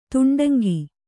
♪ tuṇḍaŋgi